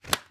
Helmet and visor sounds
visor_up1.ogg